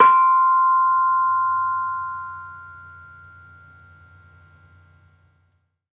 Bonang-C5-f.wav